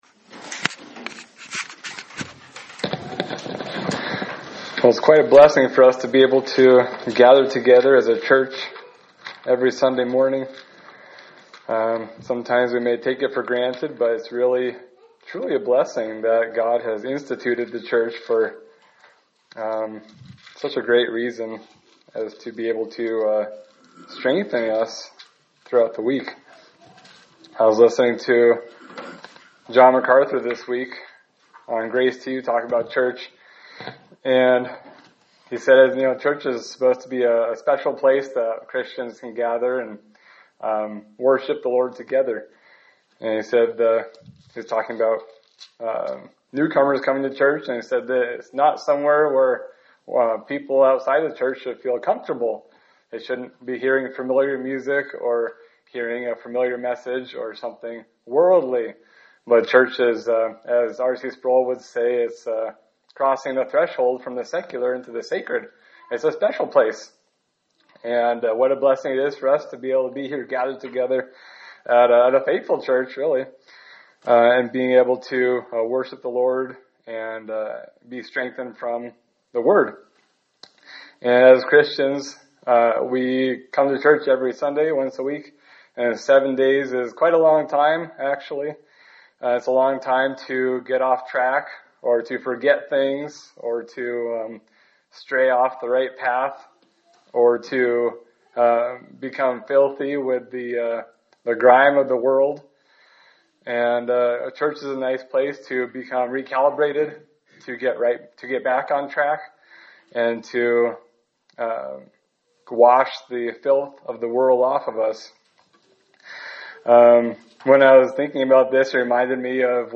Sermon for June 1, 2025
Service Type: Sunday Service